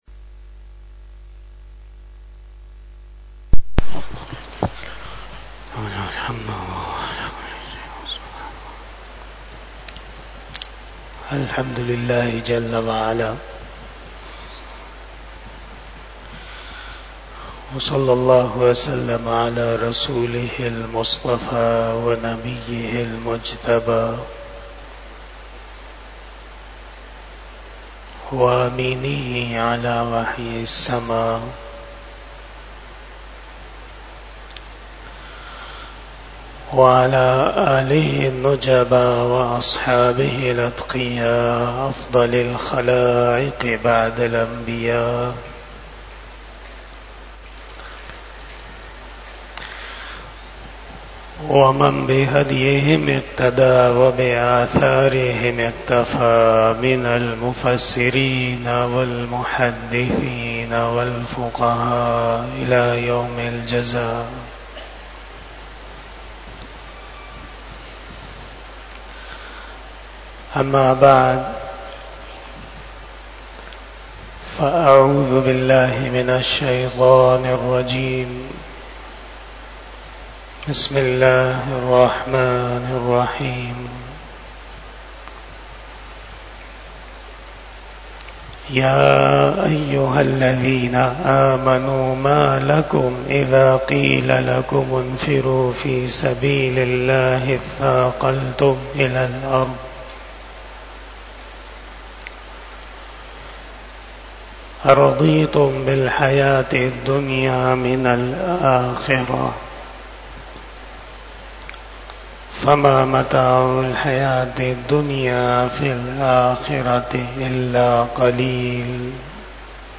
31 Bayan E Jummah 01 August 2024 (26 Muharram 1446 HJ)
Khitab-e-Jummah